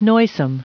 Prononciation du mot noisome en anglais (fichier audio)
Prononciation du mot : noisome